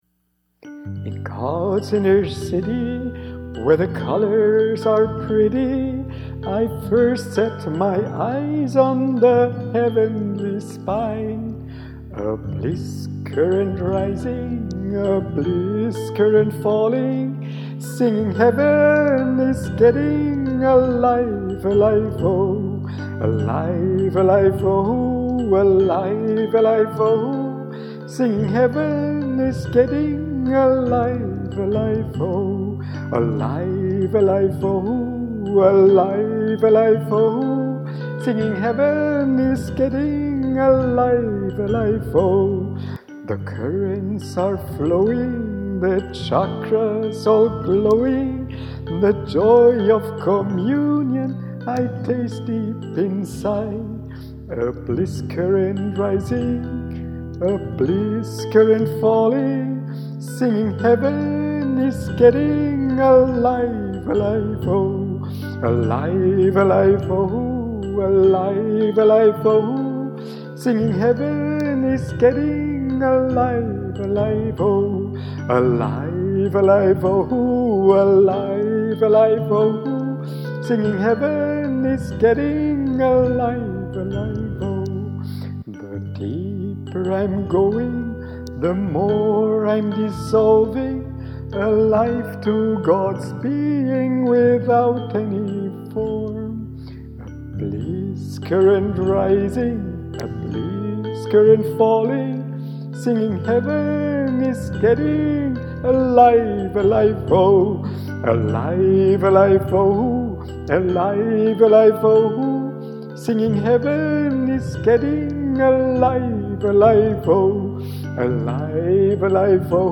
This is just for fun… it’s an Irish folklore song transformed into a special KRIYA-BLISS-SONG.
So get your voice started now… as here the Kriya-singalong starts: